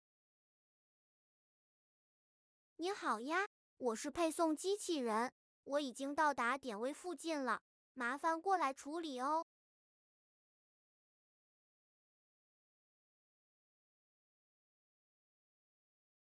通知音响的音频文件支持自定义，默认使用了30%音量大小的播报语音，如果现场环境嘈杂，可以自行更新更大音量的音频。